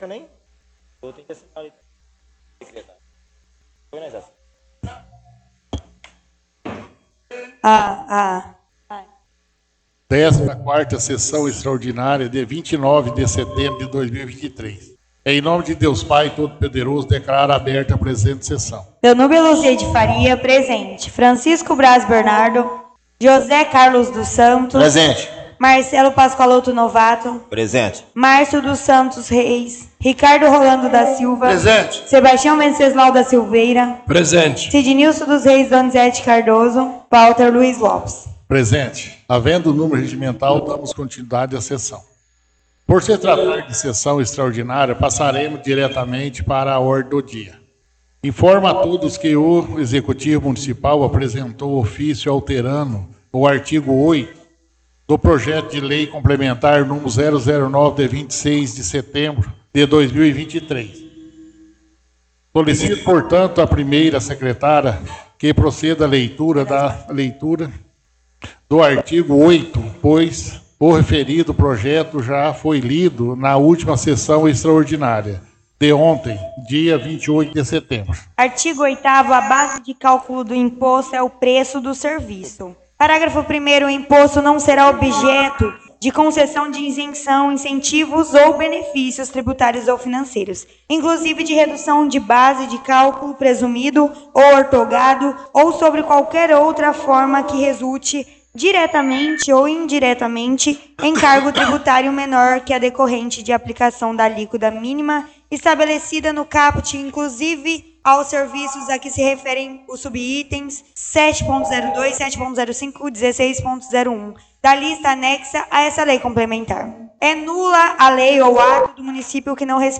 Áudio 14ª Sessão Extraordinária – 29/09/2023